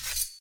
take_sword.ogg